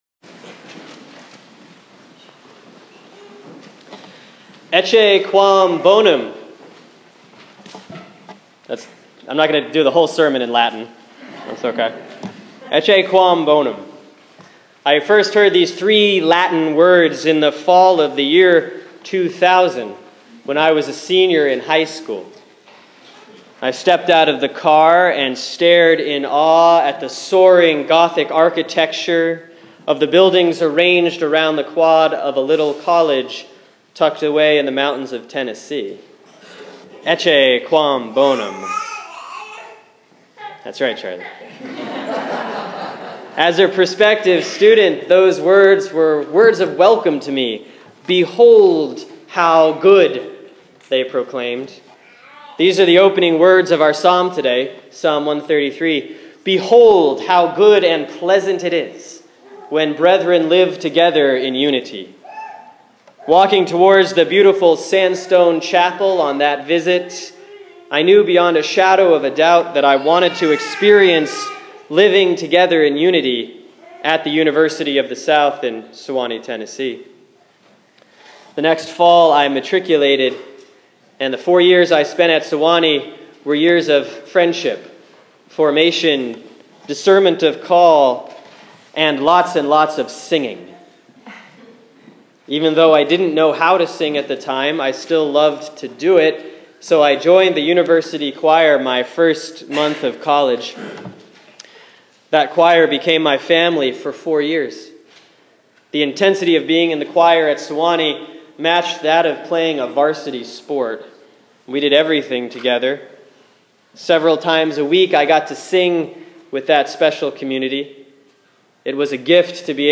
Sermon for Sunday, April 12, 2015 || Easter 2B; Psalm 133